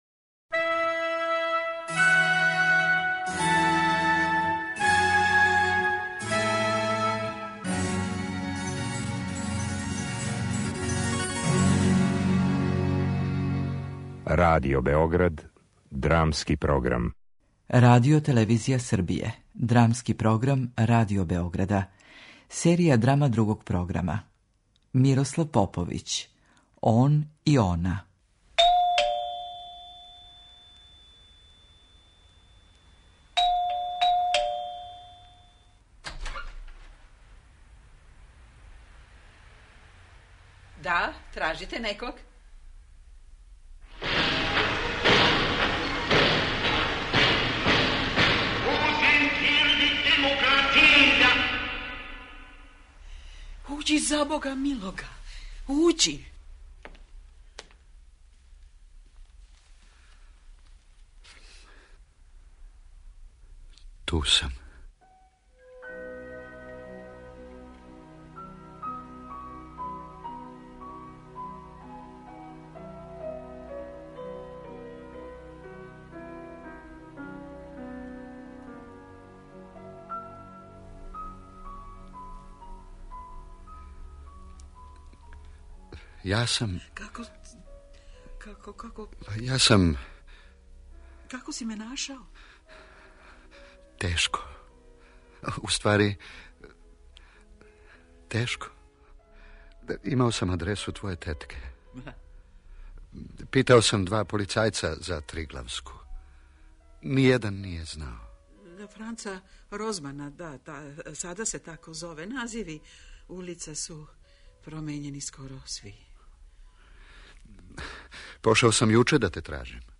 Драма
Дуо драма писца Мирослава Поповића о поновном сусрету мушкарца и жене након тридесет година.